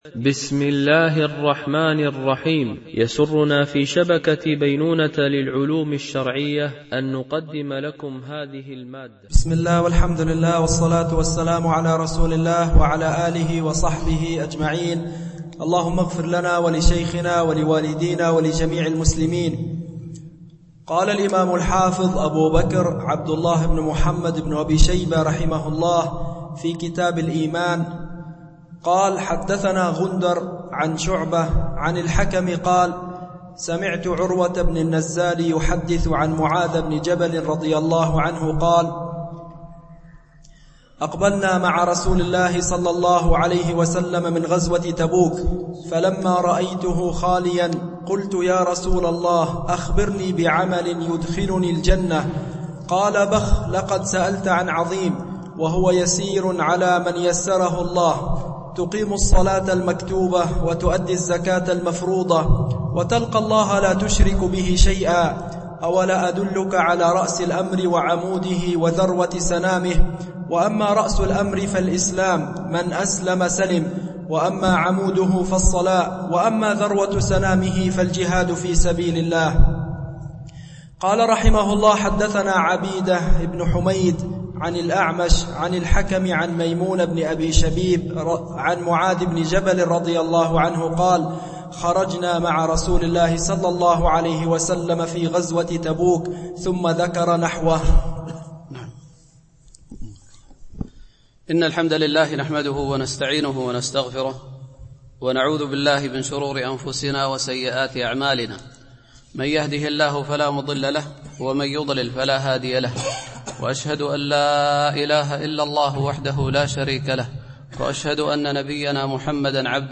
شرح كتاب الإيمان لابن أبي شيبة ـ الدرس 2 (الحديث 1 - 3)